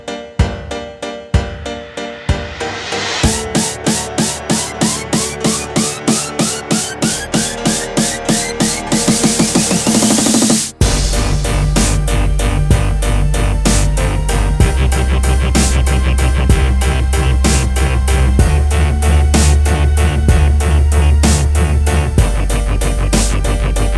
With Explicit Backing Vocals Pop (2010s) 3:25 Buy £1.50